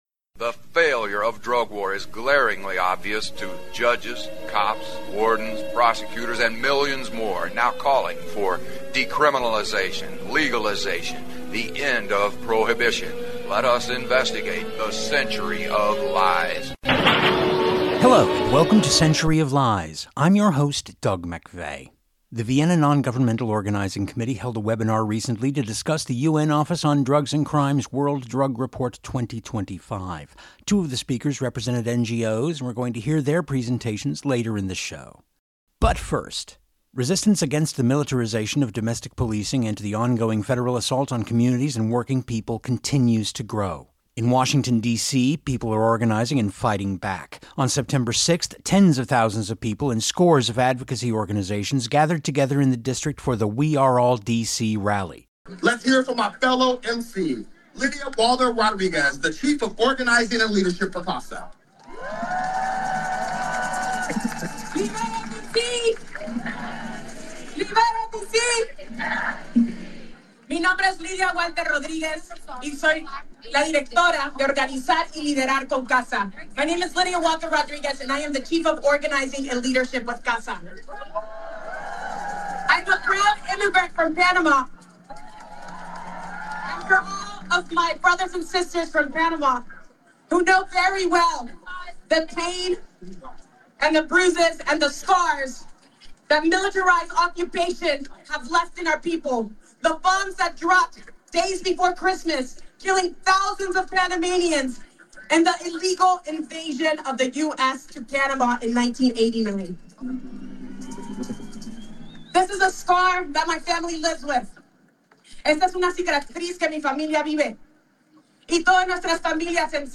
The Vienna NonGovernmental Organizing Committee held a webinar recently to discuss the UN Office on Drugs and Crime’s World Drug Report 2025.